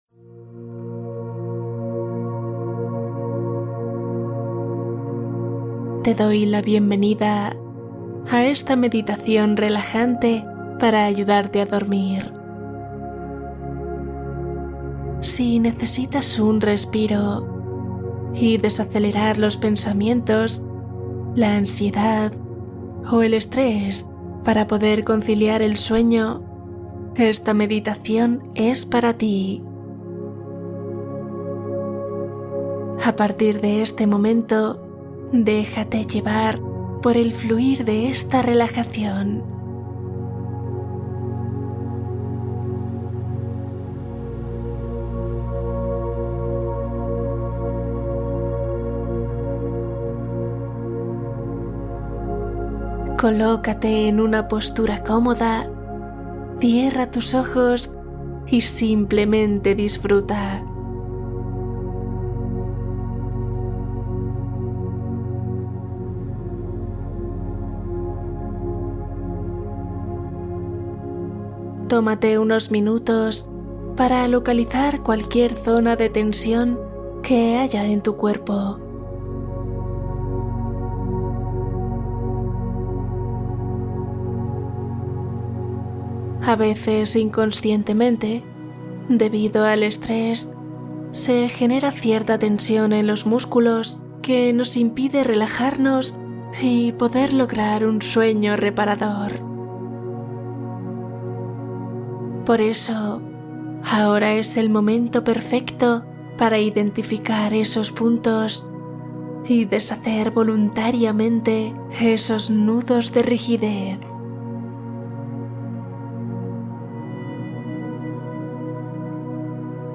Meditación de 1 hora para sueño profundo | Relajación nocturna total